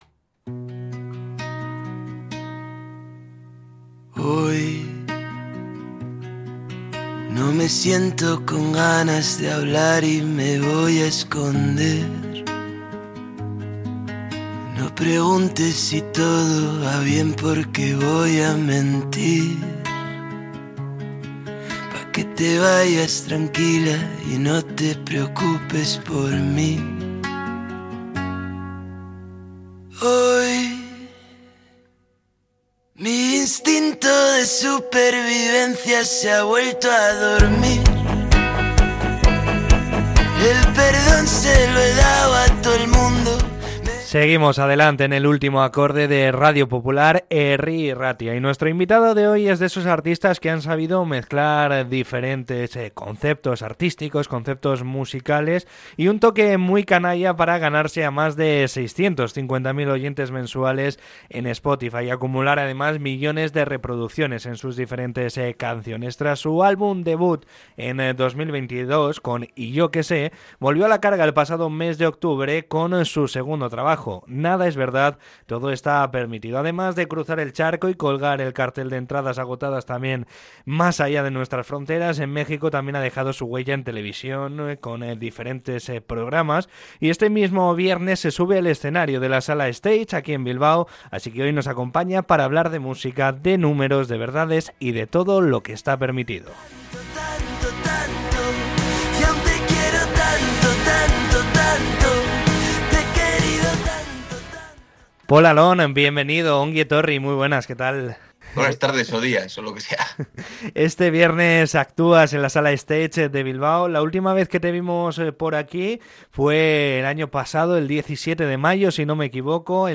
Entrevista con el cantante navarro